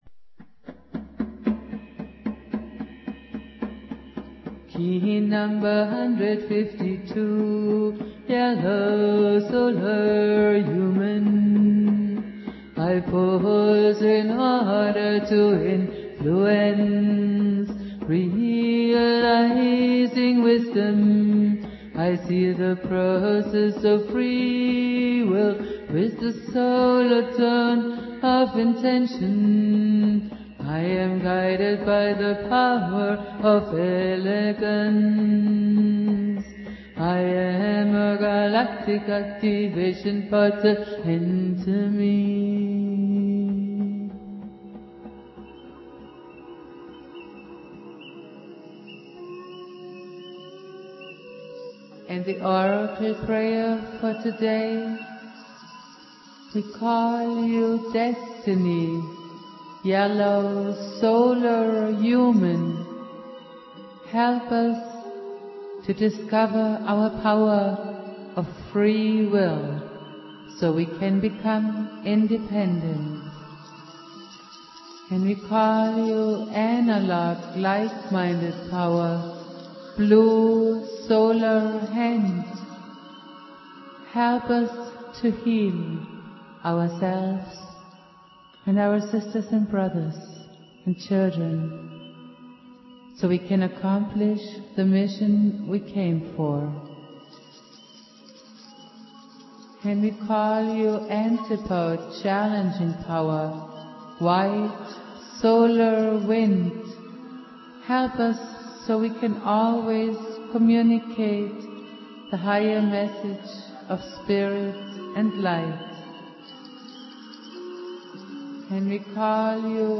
Jose Arguelles - Valum Votan playing flute.
Prayer